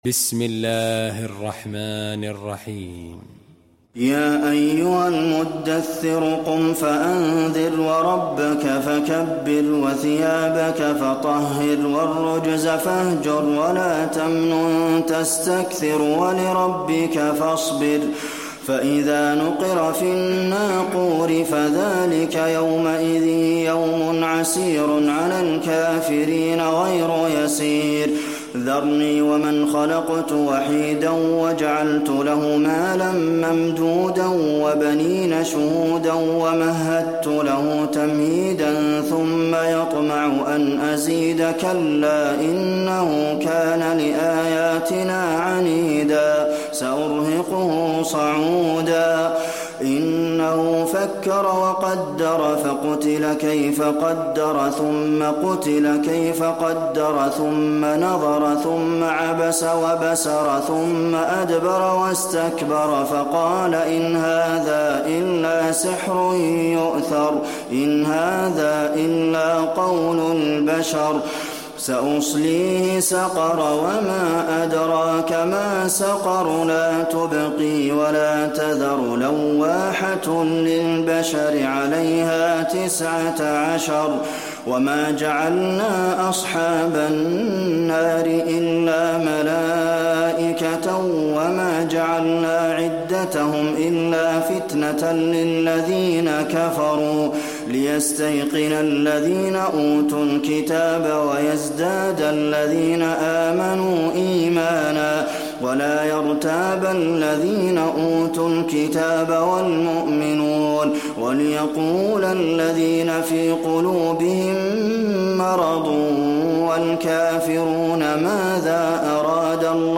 المكان: المسجد النبوي المدثر